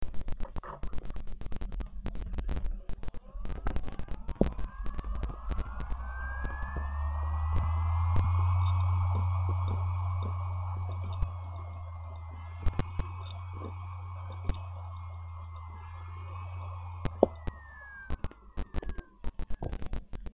Il disco parte e si ferma dopo circa 20 secondi, se scollego l'usb lasciando alimentazione attaccata il disco si riaccende, 20 sec e si rispegne.
Ad orecchio sento come se per 3 volte tenta di partire e poi si spegne.
il suono è un po' sporco di interferenze, ma è comprensibile.
SUONO HDD
hdd-1.mp3